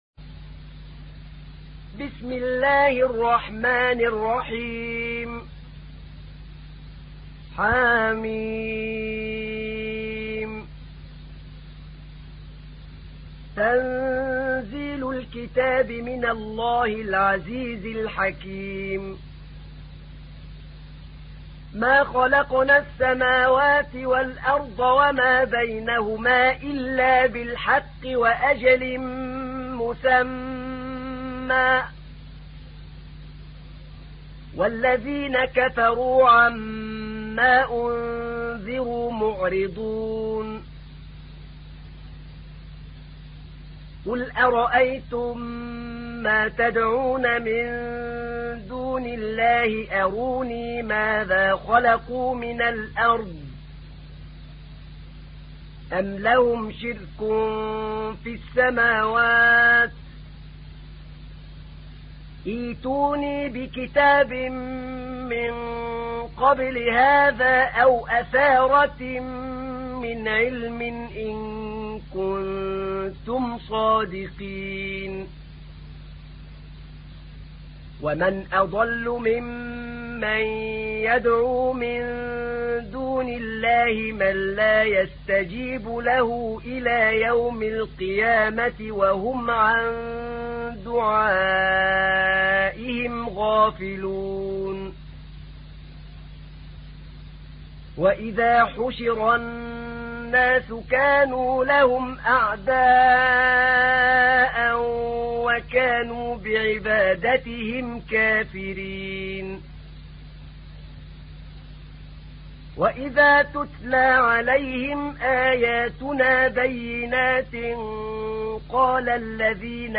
تحميل : 46. سورة الأحقاف / القارئ أحمد نعينع / القرآن الكريم / موقع يا حسين